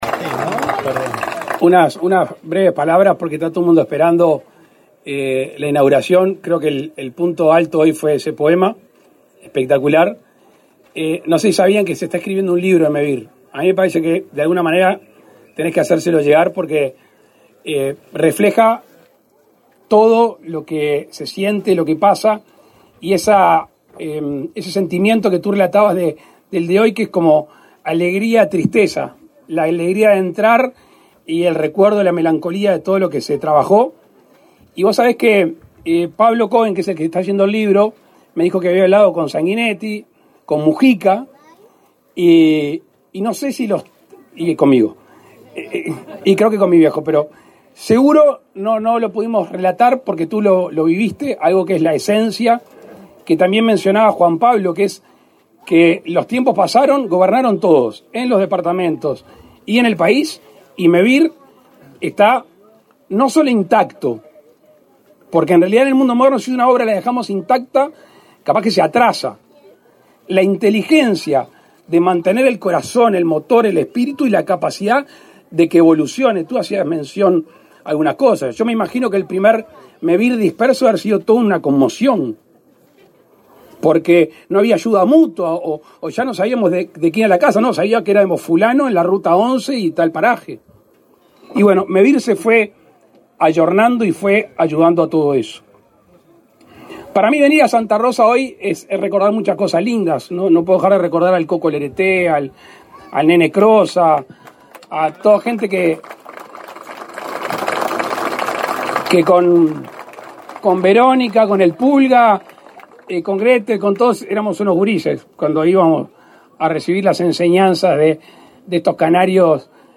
Palabras del presidente de la República, Luis Lacalle Pou
Palabras del presidente de la República, Luis Lacalle Pou 28/03/2023 Compartir Facebook X Copiar enlace WhatsApp LinkedIn El presidente de la República, Luis Lacalle Pou, participó, este 28 de marzo, en la inauguración de 44 viviendas de Mevir en la localidad de Santa Rosa, en Canelones.